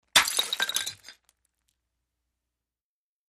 China bowl smash